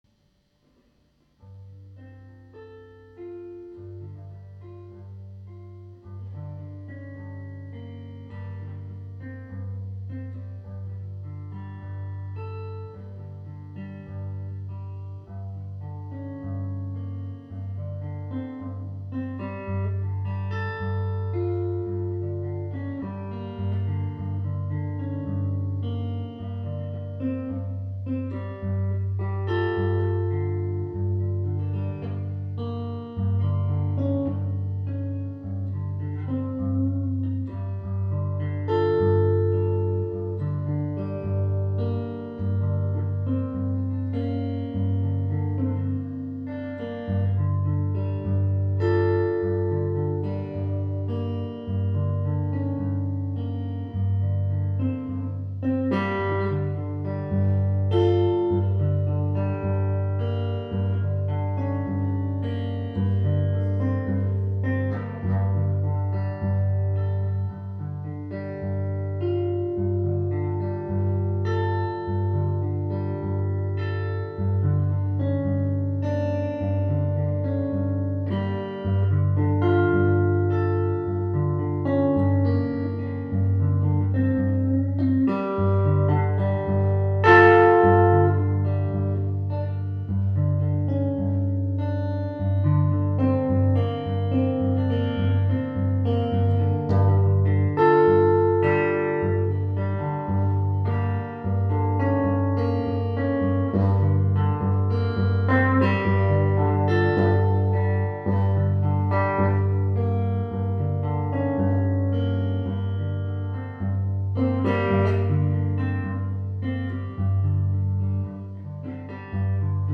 stereo
Live recorded
drums